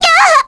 Selene-Vox_Damage_kr_02.wav